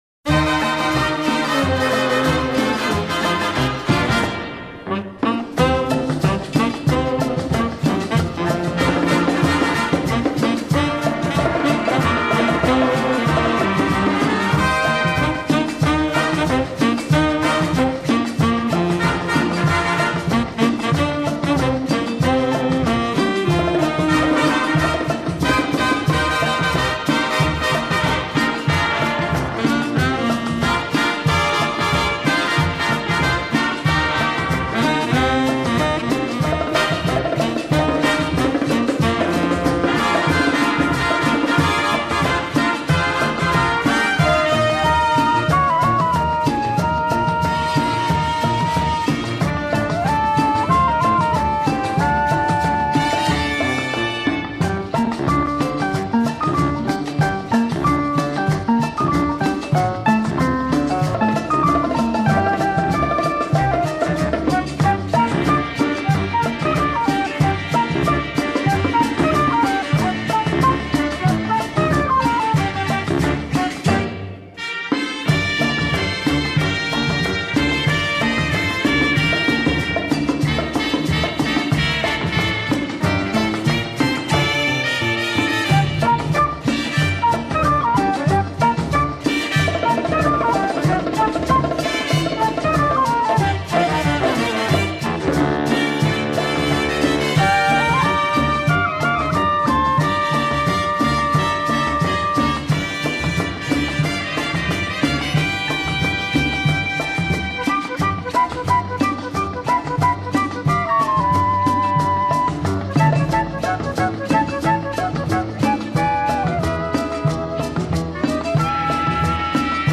Жанр: Tango, Orchestra, Easy Listening